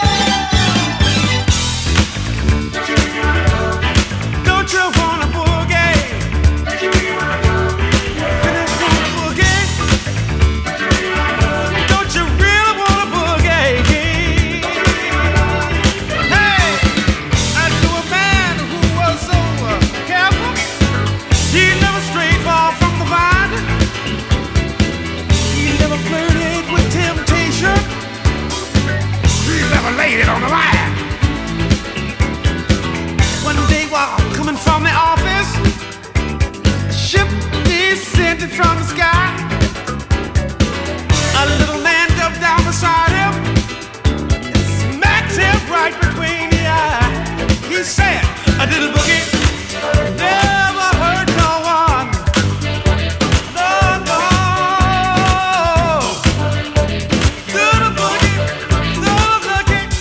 AORやブラジルもの好きにもオススメなアダルトで洗練された傑作！